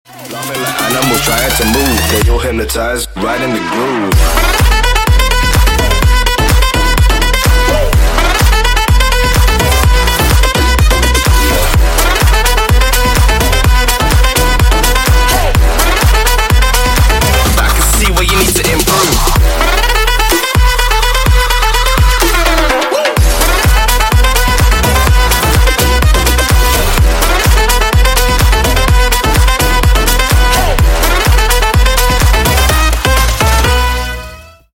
Клубные Рингтоны » # Громкие Рингтоны С Басами
Рингтоны Электроника